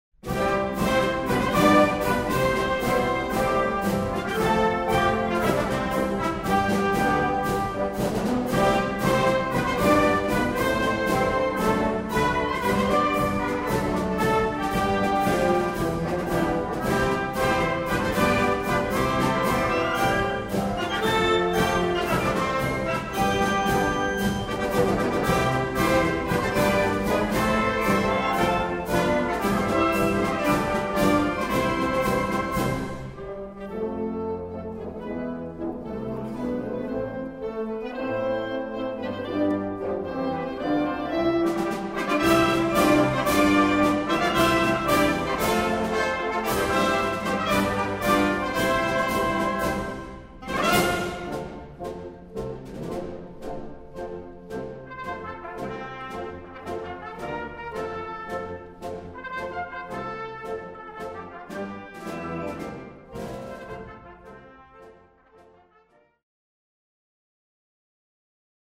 Wind Orchestra Grade 3-5